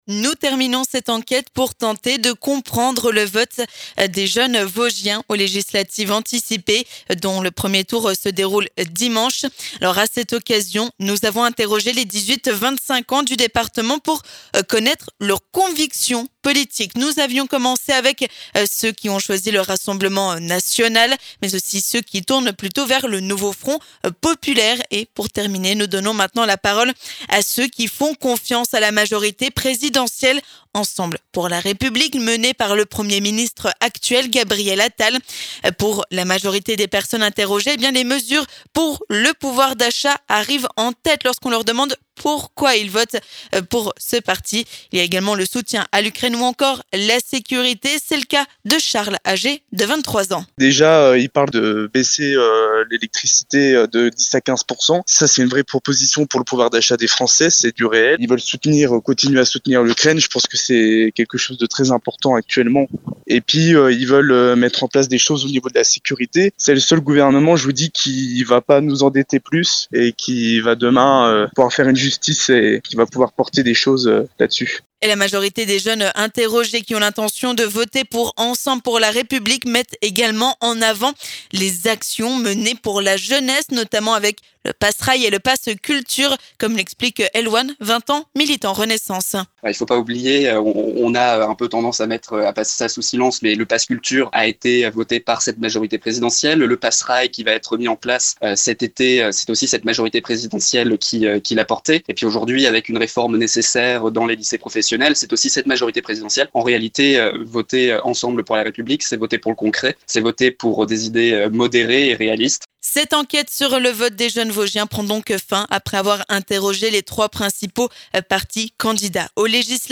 Nous continuons, sur Vosges FM, une enquête pour comprendre les votes de jeunes dans le département aux approches des élections législatives anticipées. Aujourd'hui, nous donnons la parole aux 18-25 ans qui ont décidé de voter pour la majorité présidentielle, Ensemble pour la République, lors du prochain scrutin.